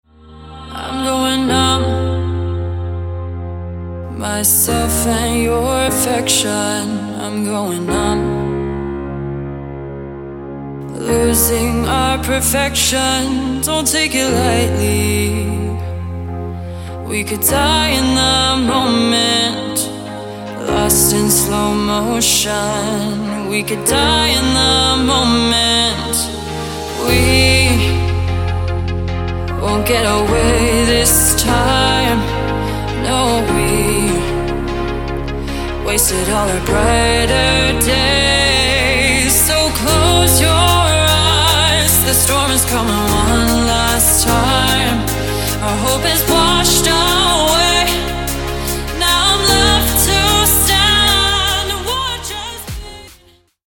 • Качество: 160, Stereo
поп
женский вокал
спокойные
ballads
romantic
vocal